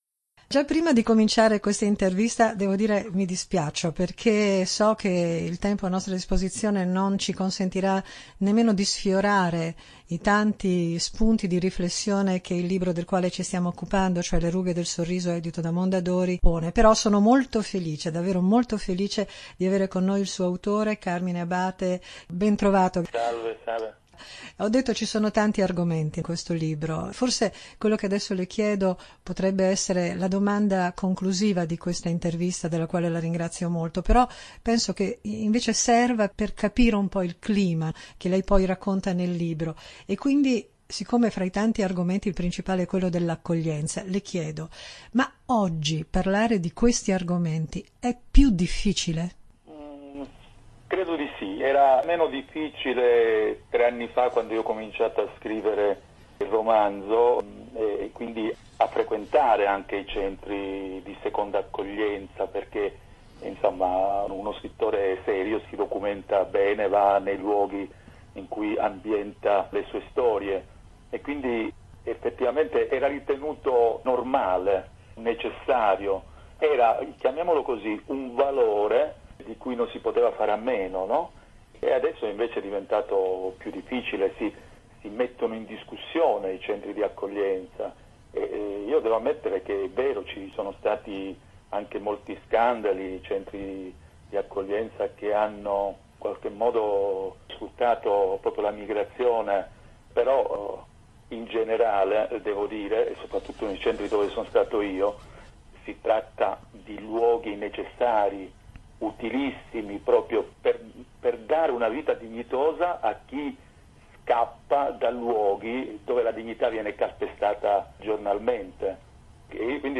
“Le rughe del sorriso”: chiacchierata con Carmine Abate